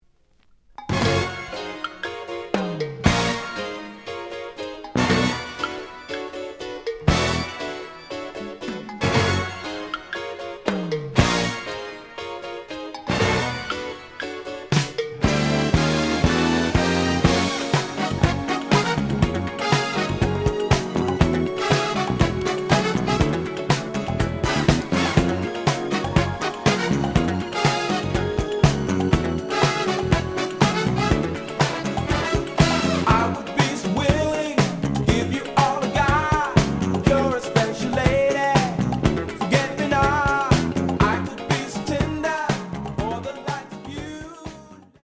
The audio sample is recorded from the actual item.
●Genre: Soul, 80's / 90's Soul
Slight edge warp.